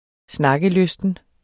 Udtale [ -ˌløsdən ]